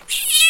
Ringetone Kat Mjav Nervøs
Kategori Dyr
kat-mjav-nervos.mp3